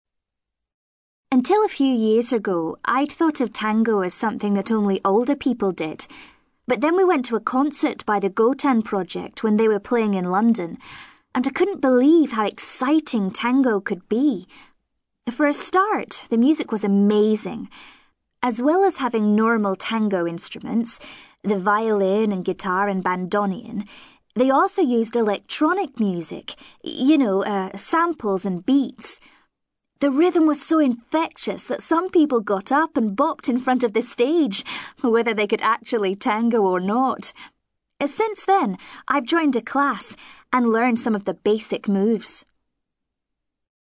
ACTIVITY 183: You will hear five short extracts in which five people are talking about an aspect of music which is or has been important to them.